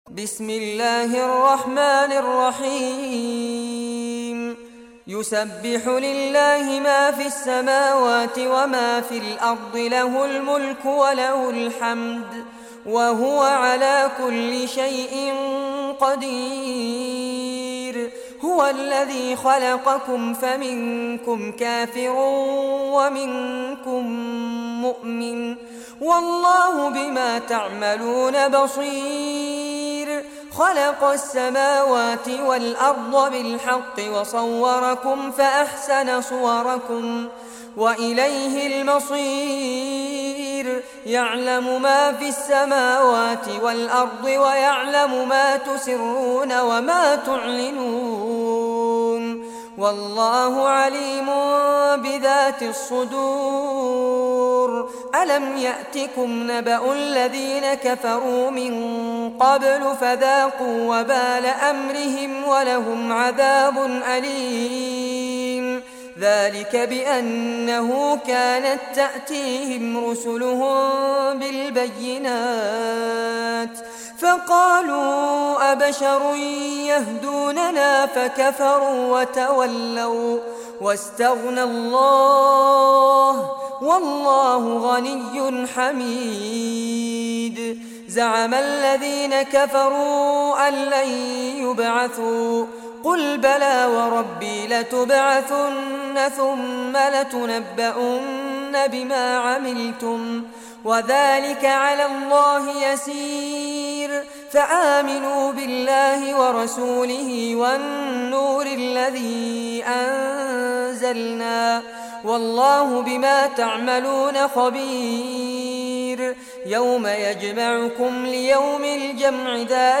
Surah At-Taghabun Recitation by Fares Abbad
Surah At-Taghabun, listen or play online mp3 tilawat / recitation in Arabic in the beautiful voice of Sheikh Fares Abbad.